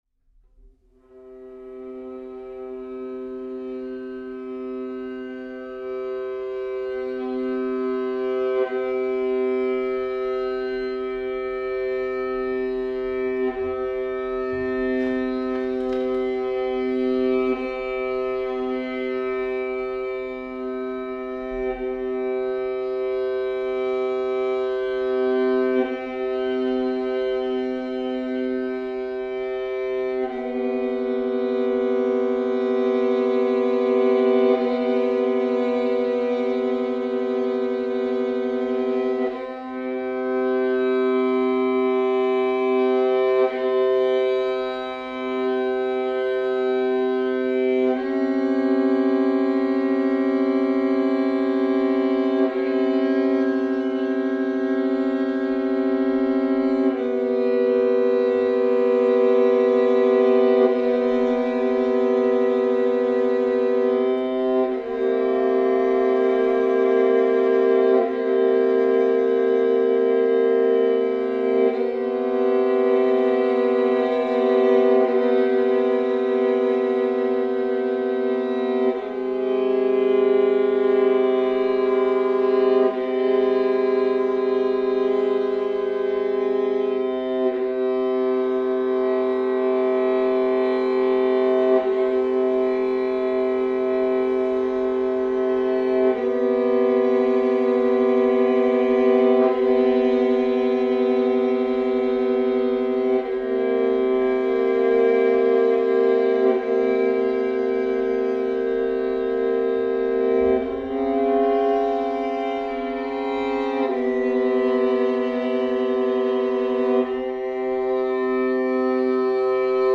improvising at Wilton’s Music Hall
Viola-under.mp3